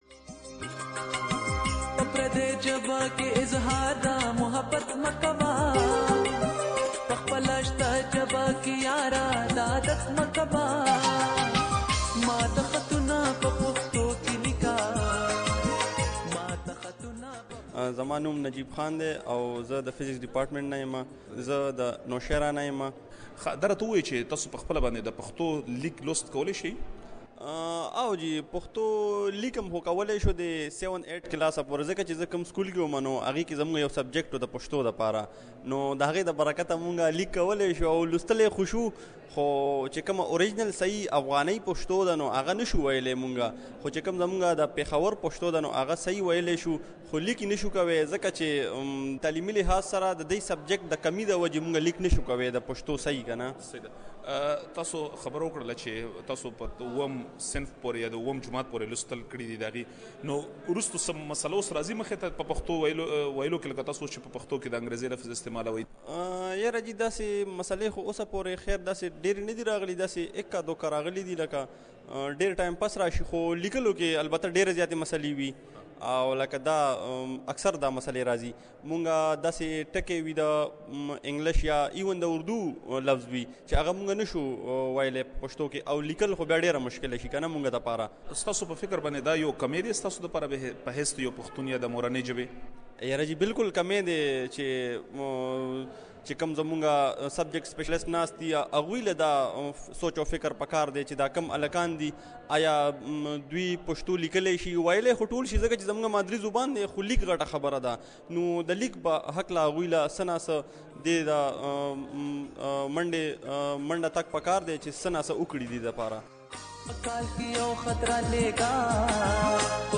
يو په زړه پورې رپوټ يې جوړ کړی چې دلته يې اوريدلی شئ